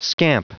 Prononciation du mot scamp en anglais (fichier audio)
Prononciation du mot : scamp